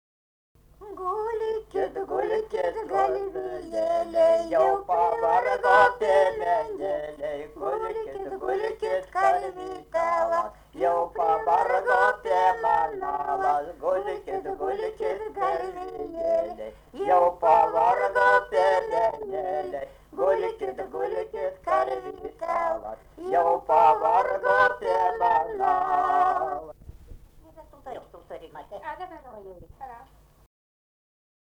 daina, vestuvių
Erdvinė aprėptis Bukonių k.
Atlikimo pubūdis vokalinis